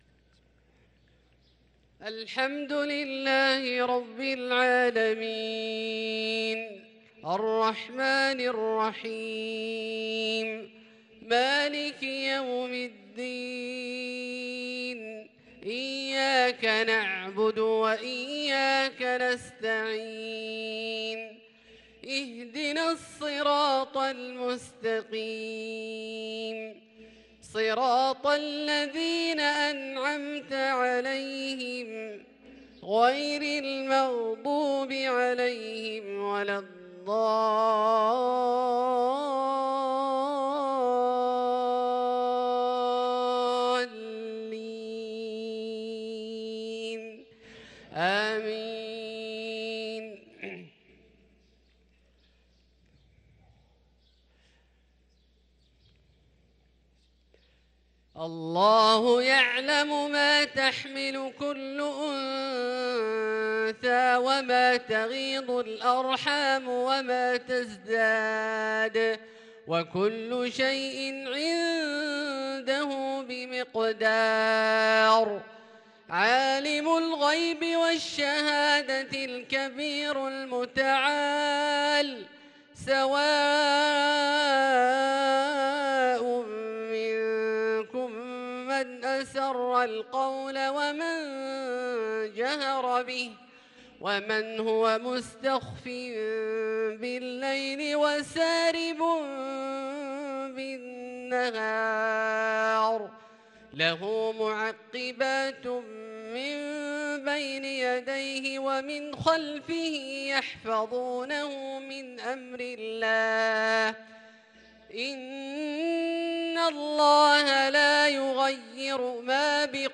صلاة العشاء للقارئ عبدالله الجهني 9 جمادي الآخر 1444 هـ